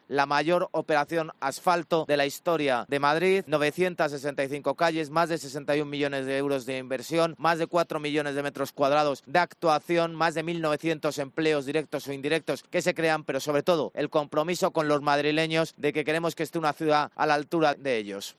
José Luis Martínez Almeida, alcalde de Madrid, sobre la operación asfalto 2021